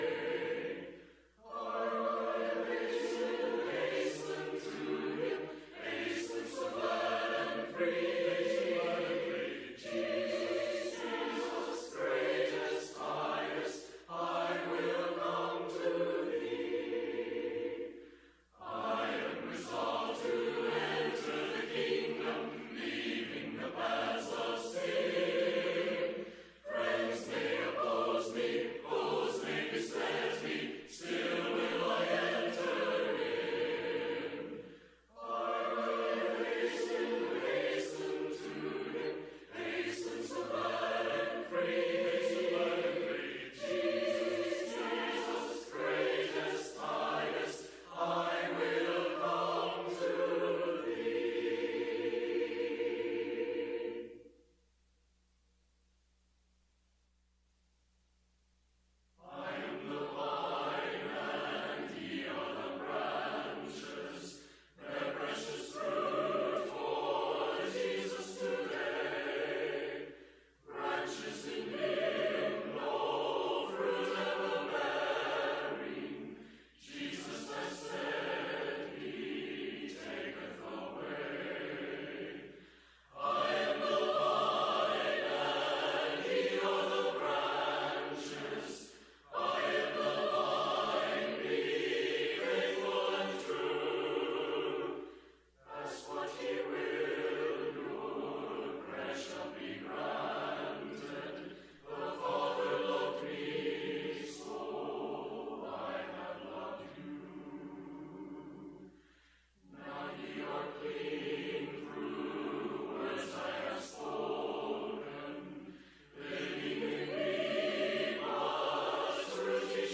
Galatians 5:26, English Standard Version Series: Sunday PM Service